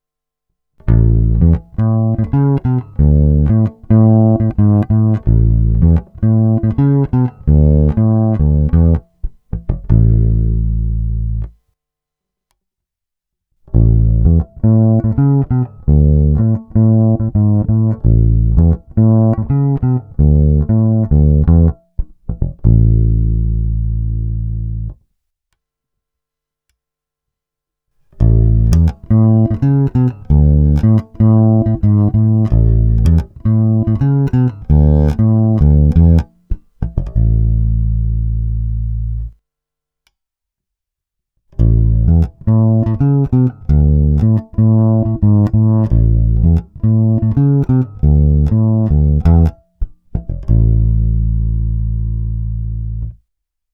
Není-li uvedeno jinak, následující nahrávky byly provedeny rovnou do zvukové karty, korekce basů, středů i výšek byly přidány cca na 1/2, tónová clona vždy plně otevřená. Hráno vždy blízko krku.
První tři ukázky mají vždy čtyři části v pořadí: 1) singl, 2) humbucker, 3) singl + piezo, 4) humbucker + piezo, přičemž mix pieza a magnetických snímačů byl cca 50/50.
Krkový snímač